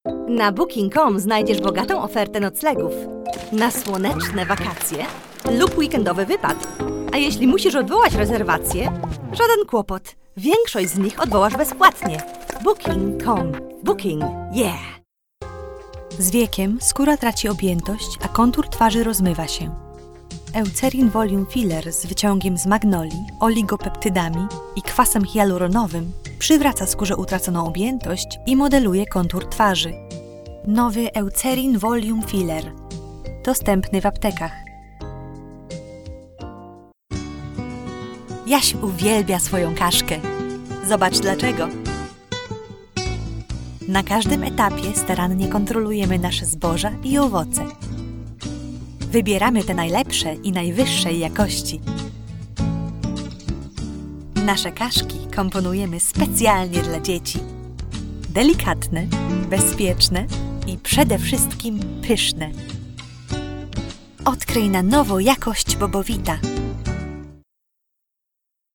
Freundlich und zuverlässig, fröhlich und kindlich oder autoritativ und ernst, meine Stimme ist perfekt für Werbung und Video Explainers (Englisch UK und Polnisch)
Sprechprobe: Werbung (Muttersprache):
Polish - Commercial Reel_0.mp3